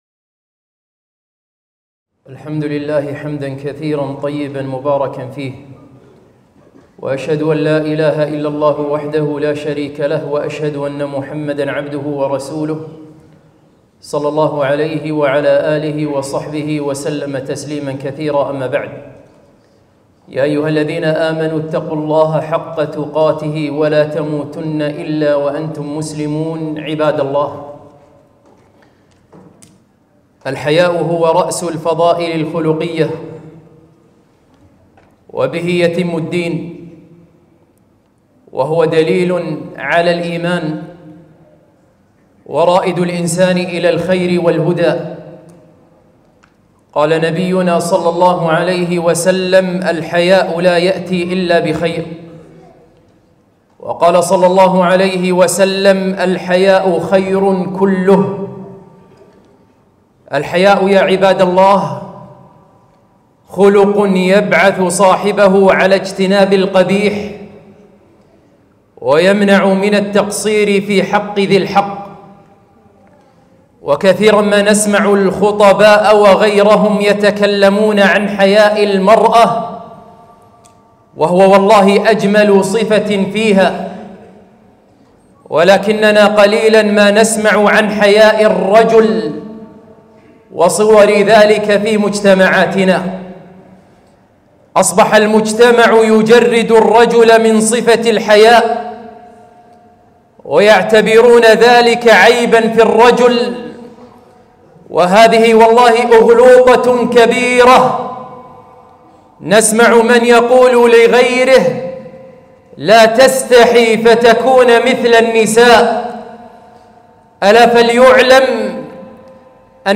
خطبة - قلة الحياء عند الرجال